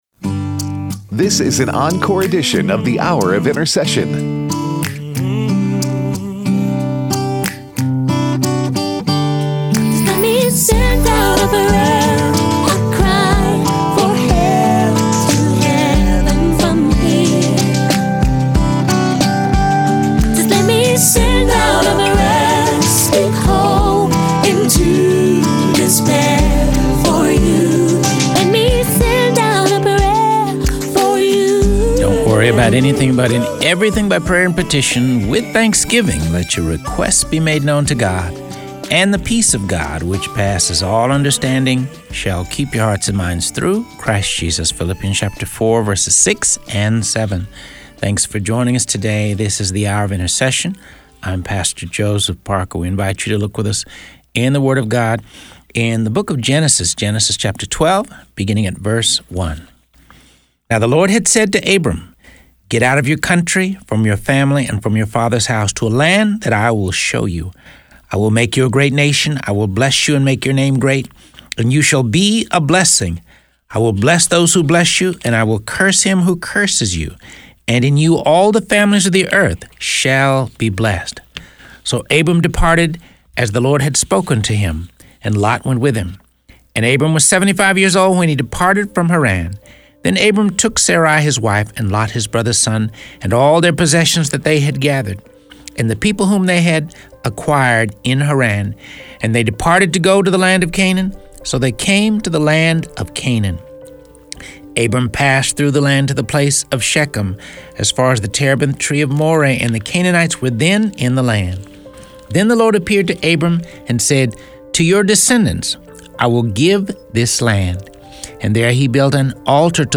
It's a fascinating interview.